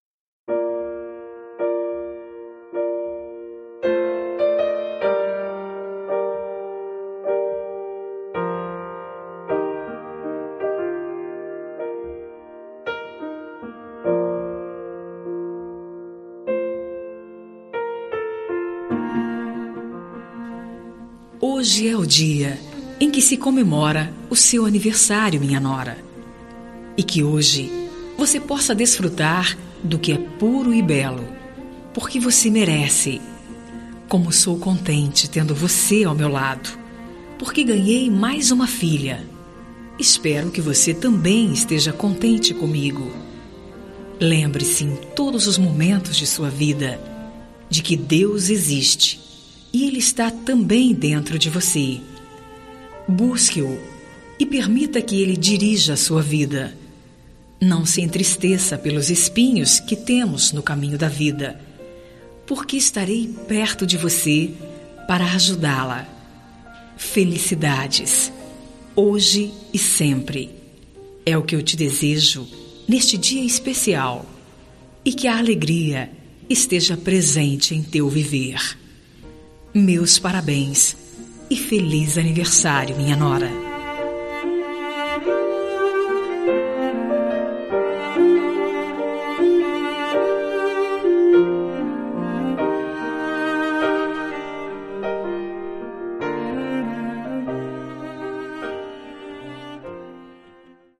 Temas com Voz Feminina